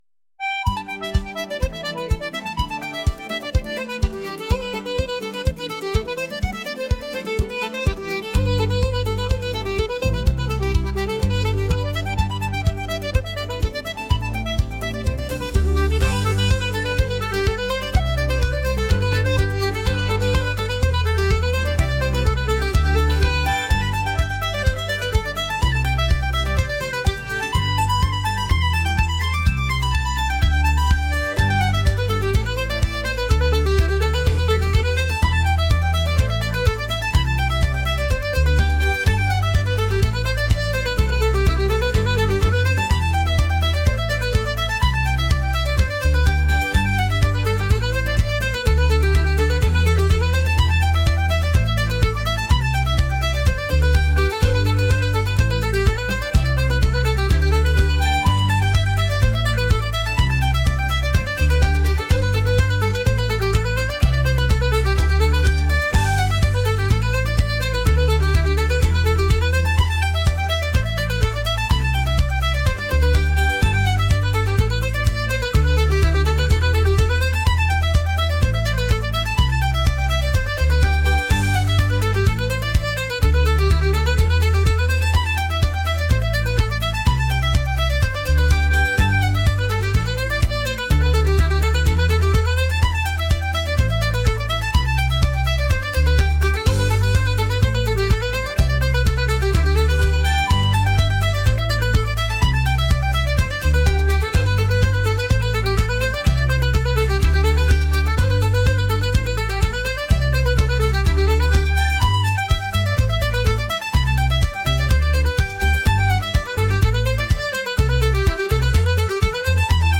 folk | energetic